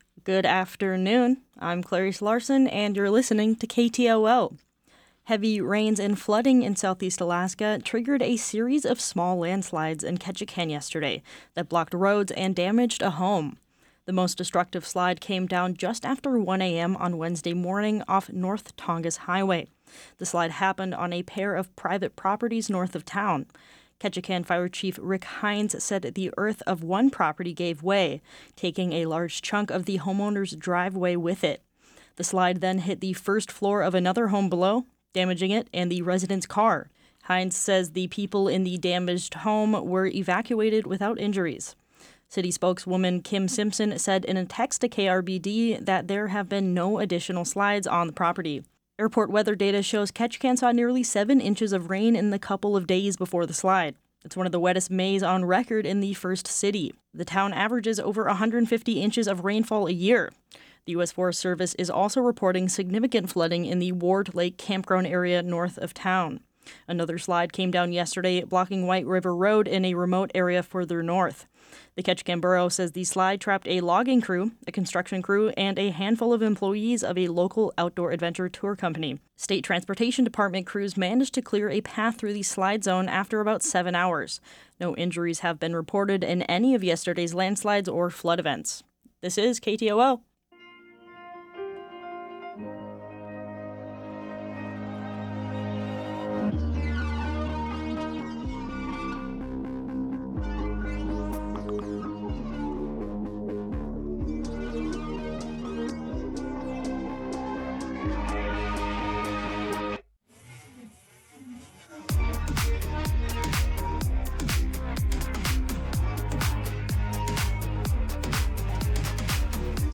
Newscast – Thursday, May 29, 2025 - Areyoupop